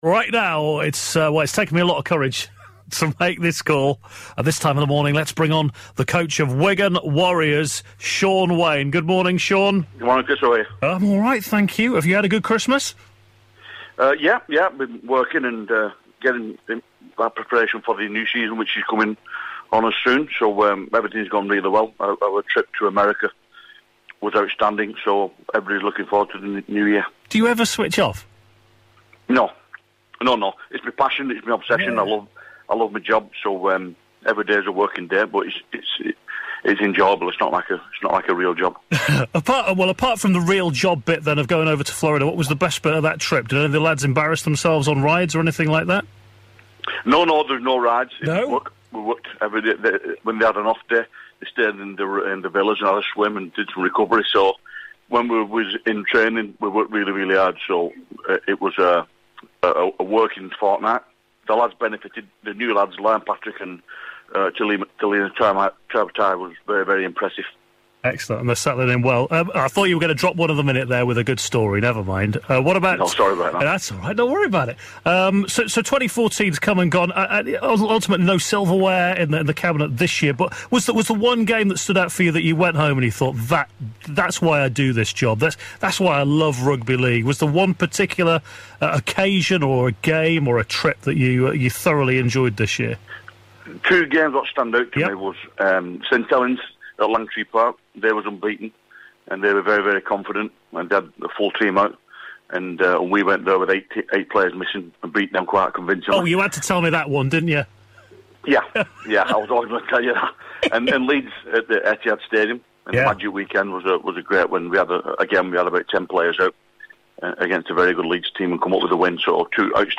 Hear the Wigan Warriors coach reflecting on 2014